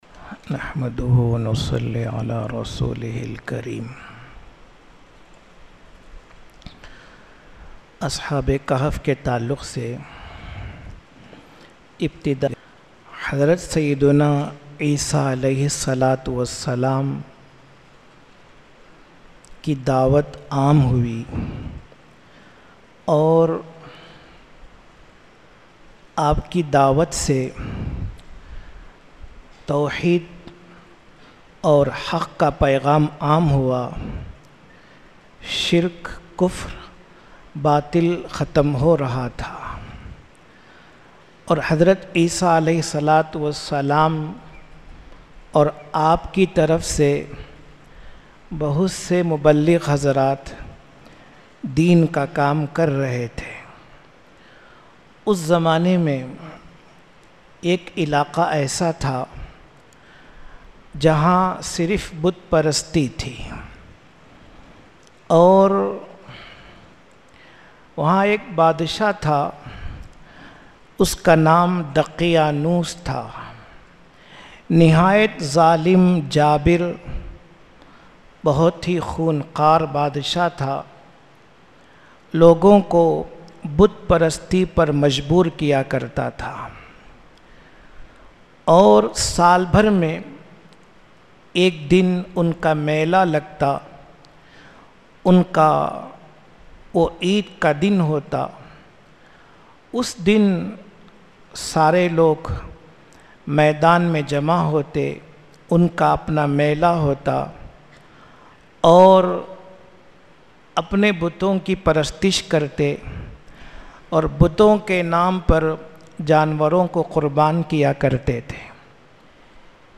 Urdu Bayans
Jamiya Mosque, Ambur.